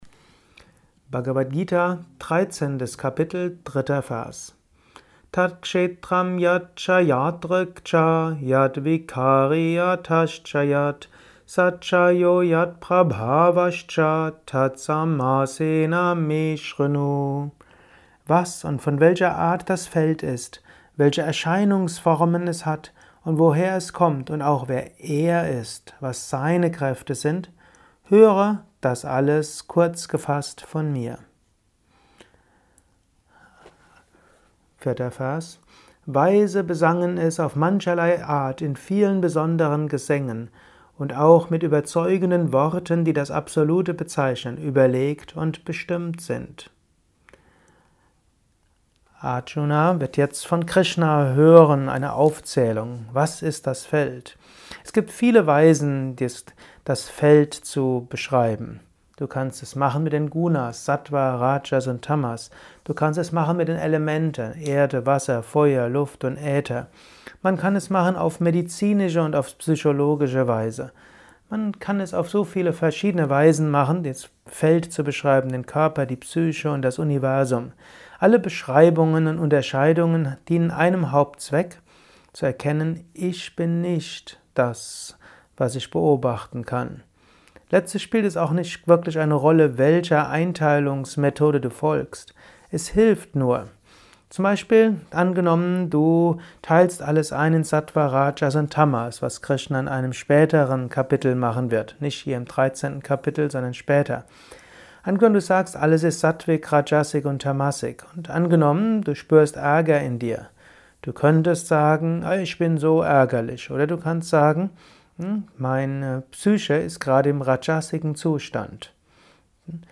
Dies ist ein kurzer Kommentar